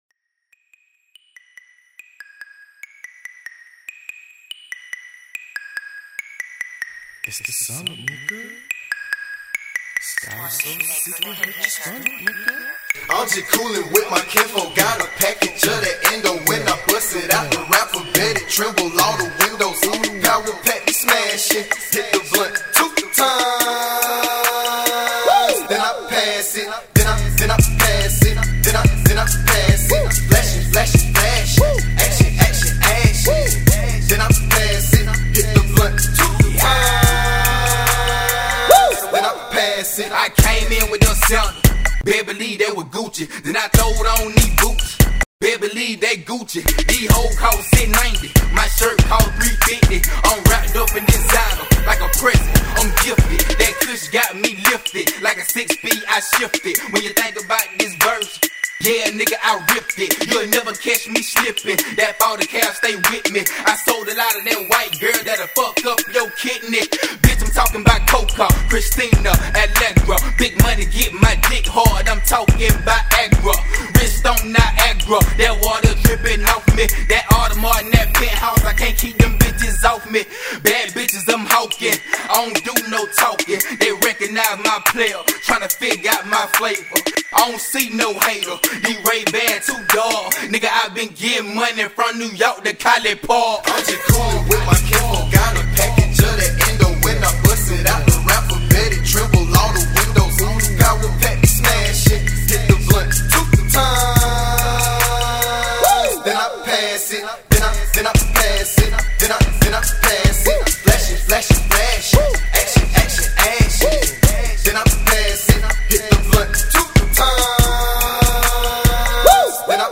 ” has very much a southern swag to it.